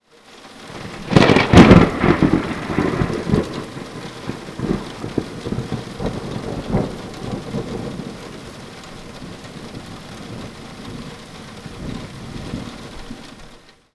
storm.wav